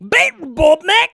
BEAT ROBOT.wav